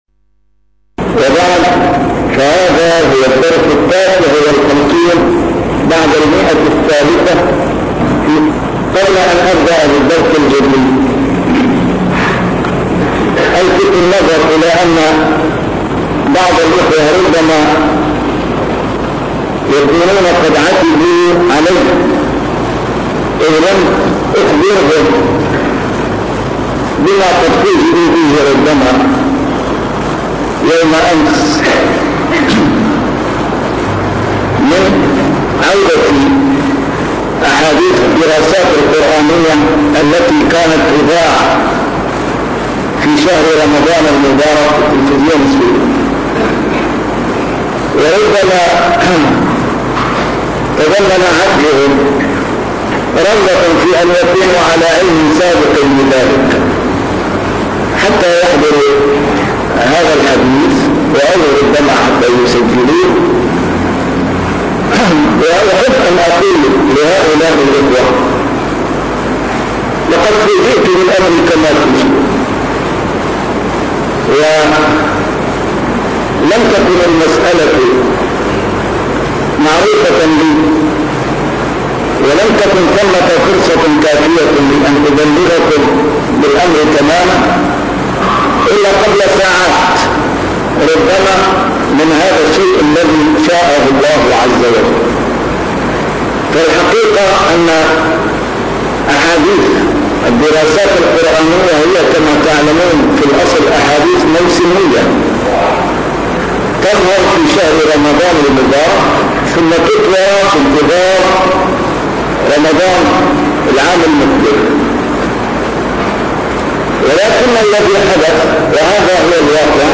شرح كتاب رياض الصالحين - A MARTYR SCHOLAR: IMAM MUHAMMAD SAEED RAMADAN AL-BOUTI - الدروس العلمية - علوم الحديث الشريف - 359- شرح رياض الصالحين: الإصلاح بين الناس